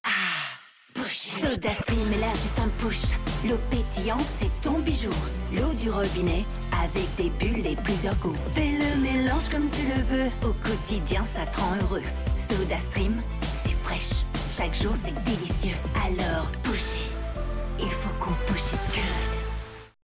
Comercial, Profundo, Amable, Cálida, Suave
Comercial